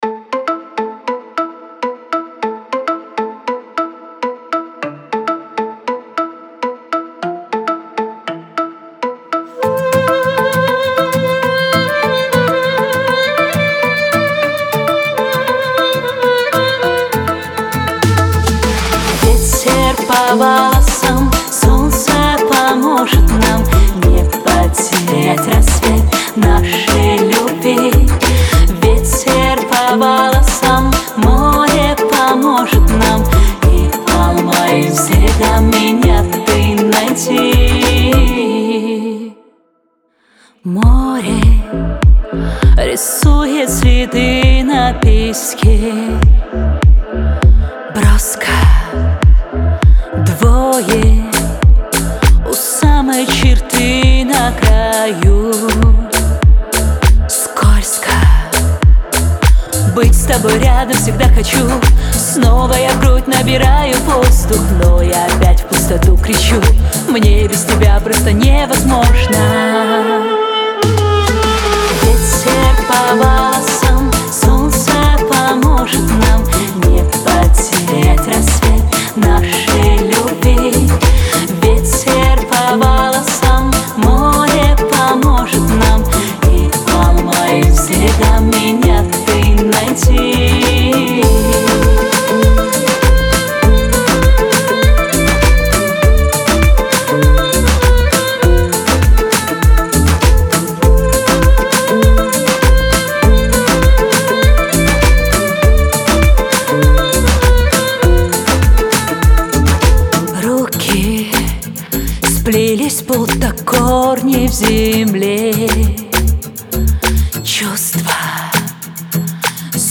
грусть , pop